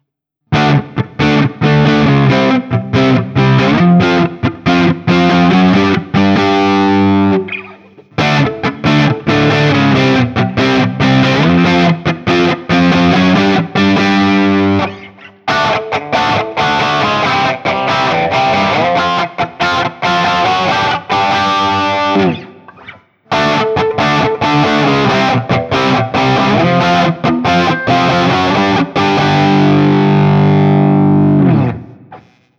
JCM-800
A Barre Chords
As usual, for these recordings I used my normal Axe-FX II XL+ setup through the QSC K12 speaker recorded direct into my Macbook Pro using Audacity.
For each recording I cycle through the neck pickup, both pickups, both pickups with phase reversed on the neck, and finally the bridge pickup.
Guild-Nightbird-I-JCM800-A-Barre.wav